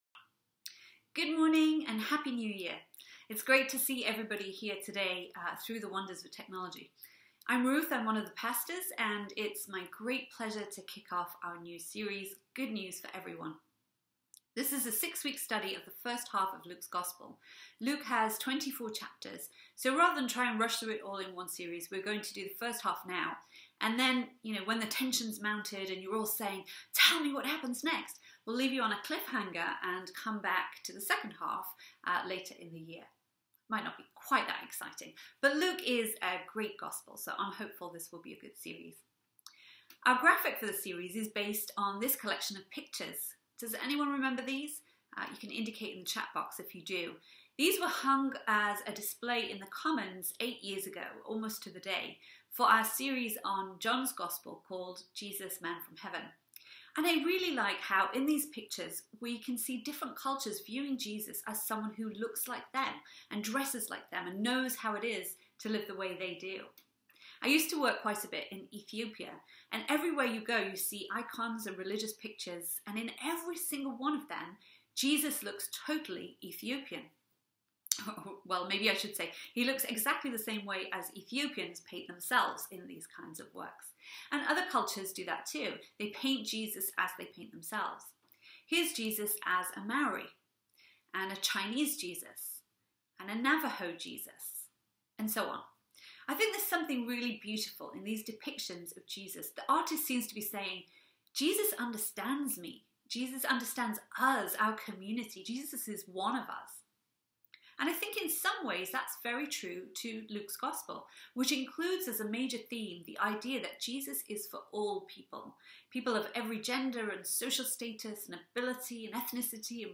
A message from the series "Next Steps." We look at the invitation to struggle with doubt, be real (more than right), "be being filled" with the Spirit, and re-engage with our church community.